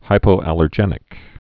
(hīpō-ălər-jĕnĭk)